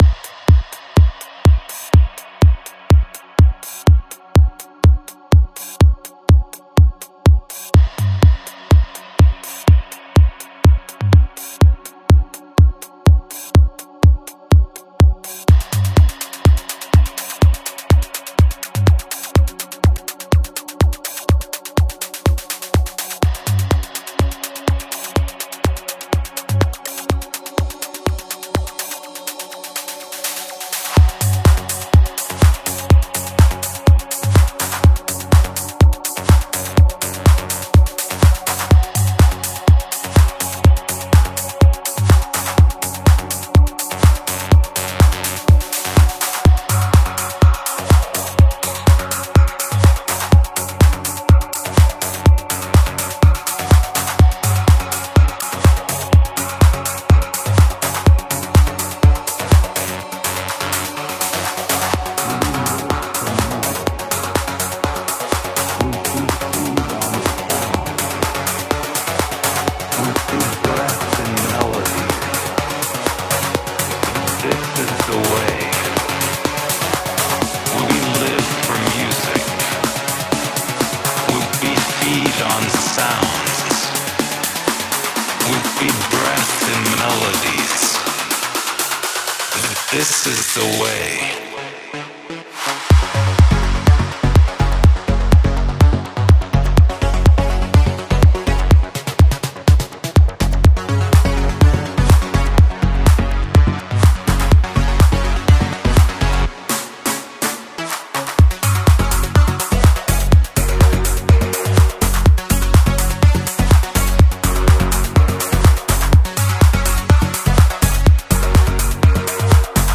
Genre: Melodic Techno